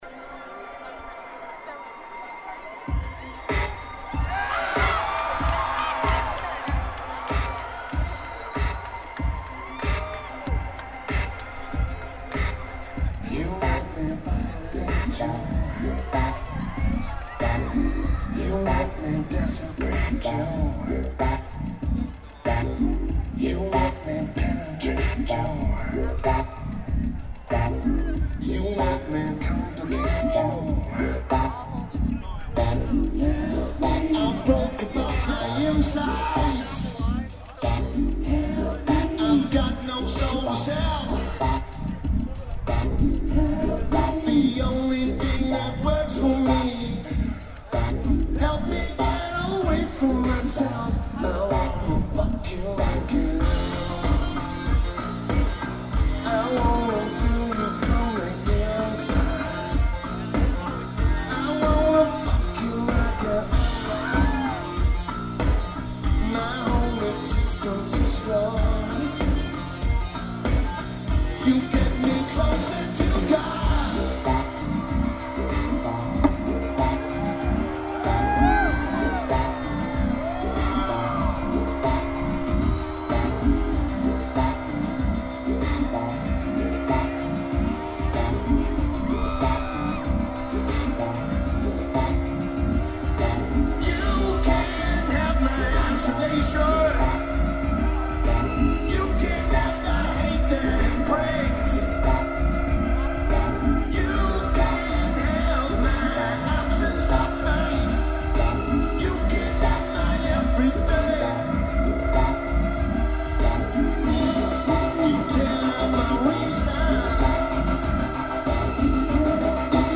Austin360 Amphitheater
Drums
Guitar
Lineage: Audio - AUD (CSC + Batt Box + Tascam DR-2d)